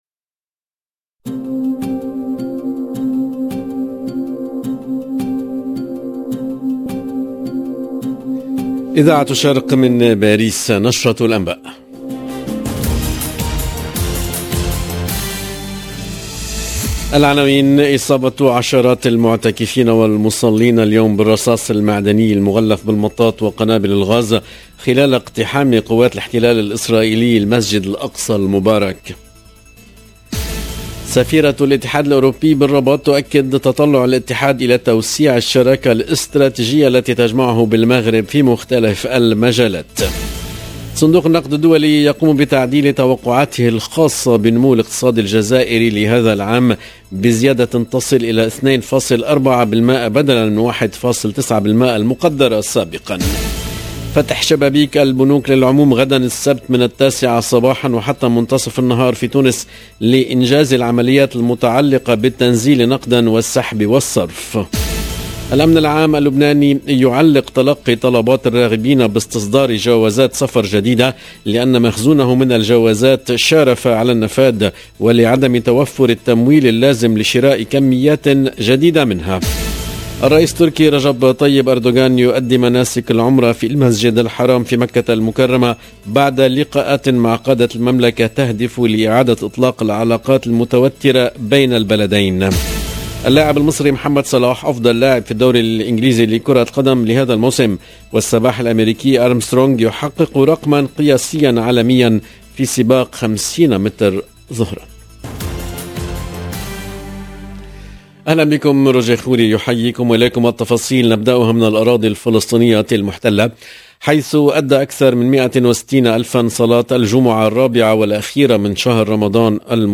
LE JOURNAL DE LA MI-JOURNEE EN LANGUE ARABE DU 29/04/22